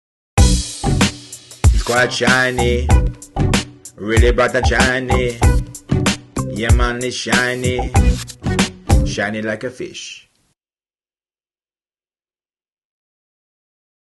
The script comes with a set of daft audio jingles / links that can be played between sketches.
BASRS_Jingle08_Urban.mp3